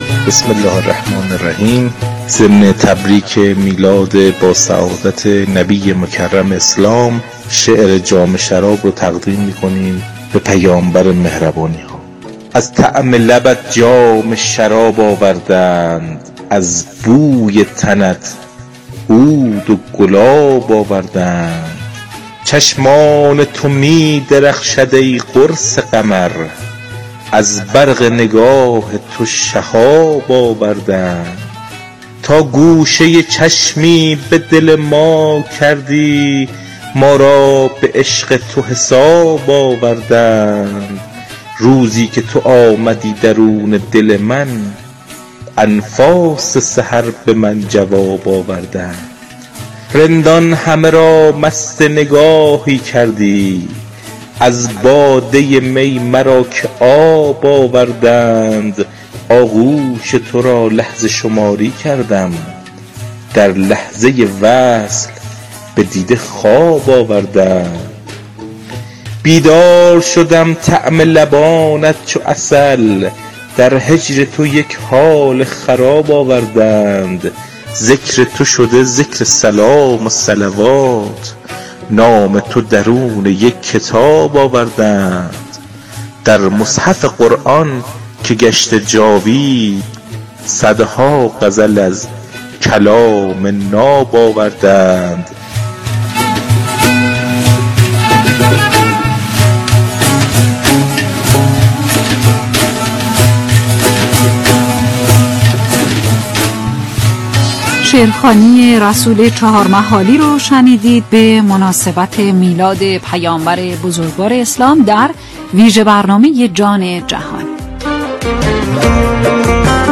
مدح میلاد پیامبر اکرم ص(جام شراب)اجرا در رادیو فرهنگ برنامه جان جهان